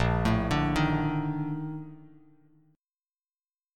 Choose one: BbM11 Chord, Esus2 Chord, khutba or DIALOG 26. BbM11 Chord